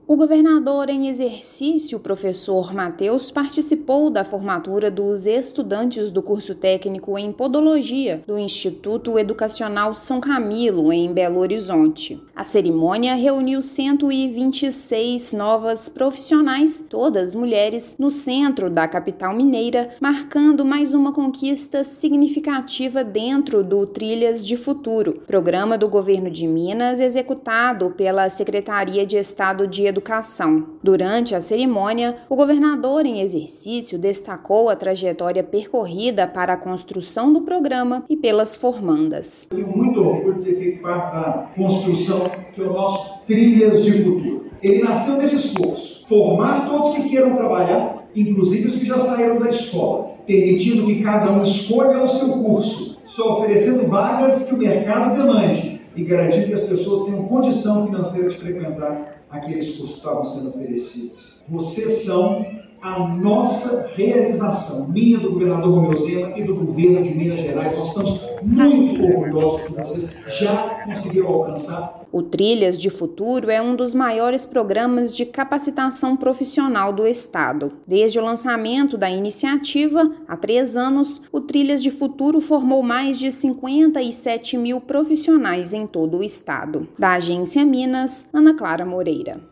[RÁDIO] Governo de Minas forma 126 estudantes de curso Técnico em Podologia do Trilhas de Futuro
Programa do Estado é iniciativa pioneira no Brasil para atender à demanda de capacitação técnica profissional. Ouça matéria de rádio.